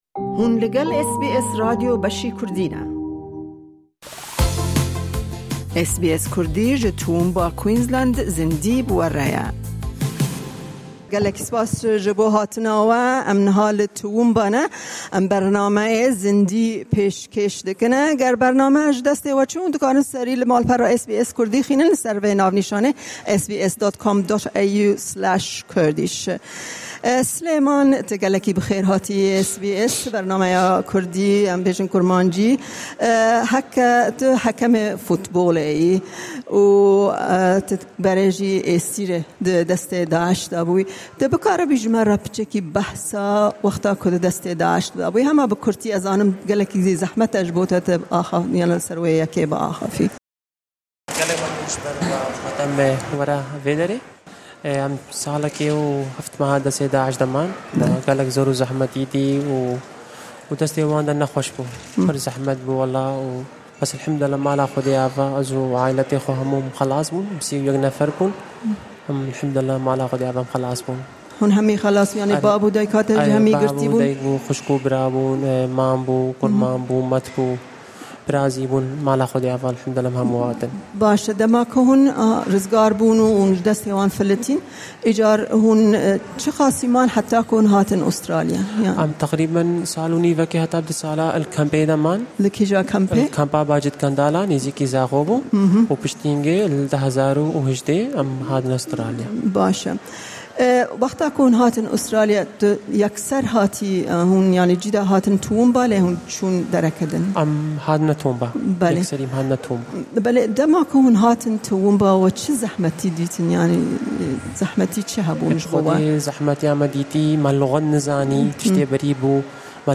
To celebrate SBS's 50th anniversay, we travelled to Toowoomba, Queensland for an Outside Broadcast.